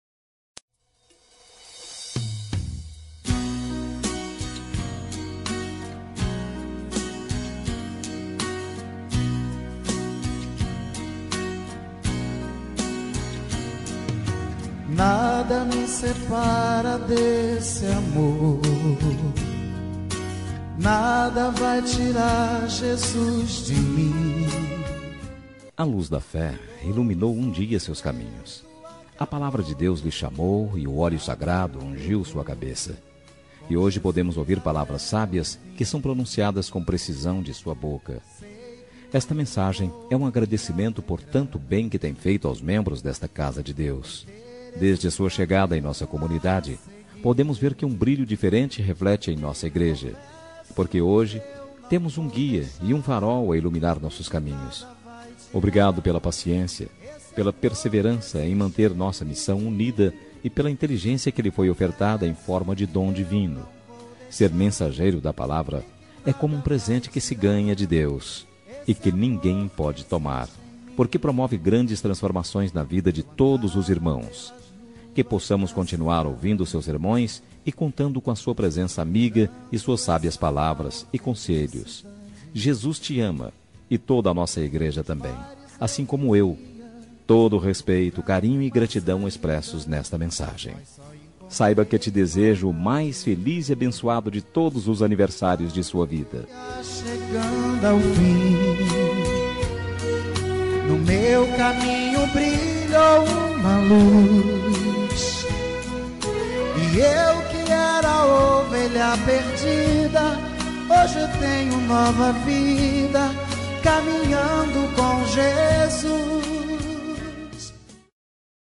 Aniversário de Pastor – Voz Masculina – Cód: 55001